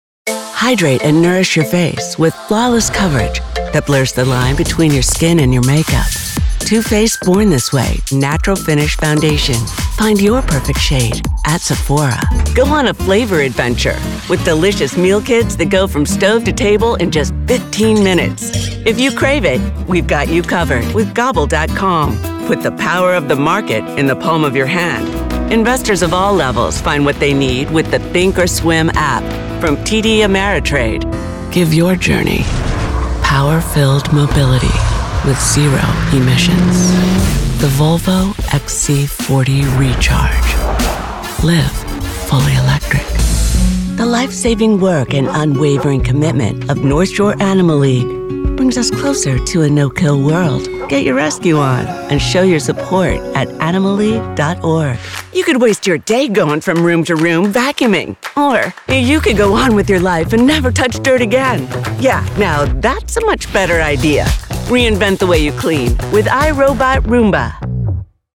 Female
Adult (30-50)
Distinctive, Modern with Charmed Nuance. can be Cheeky with Comedic timing when called for. Mid to Low is home, and can go deep but also have range to airy and clear.
Commercial - Tv, Radio, Online